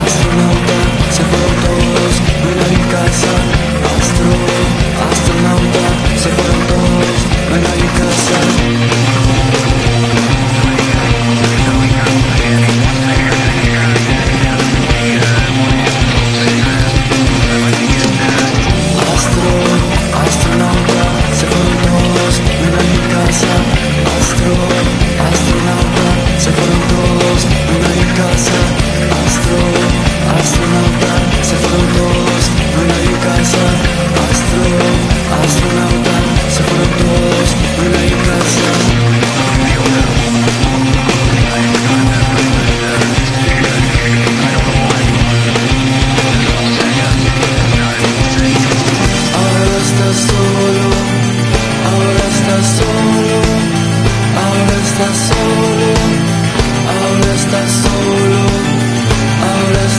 metal alternativo